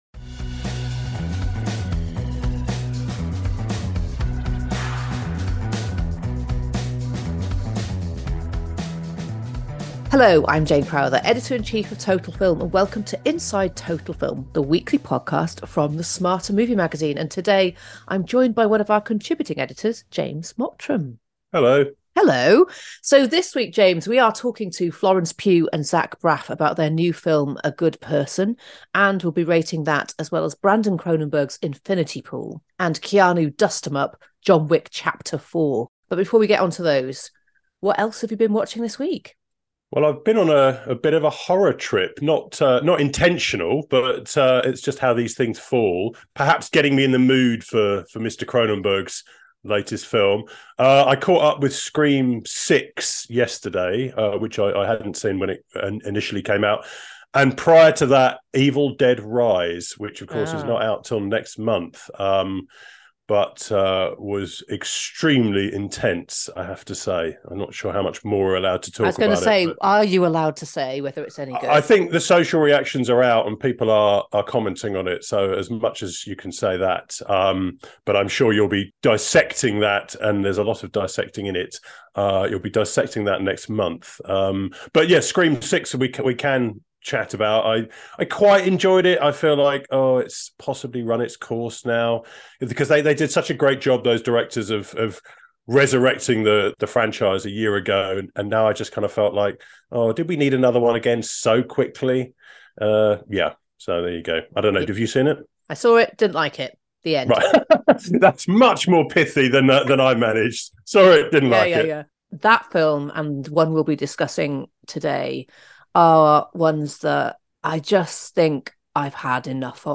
Intro and presenters discuss their picks of the week
Review of A Good Person – including interviews with the film’s director Zach Braff and the star of the movie Florence Pugh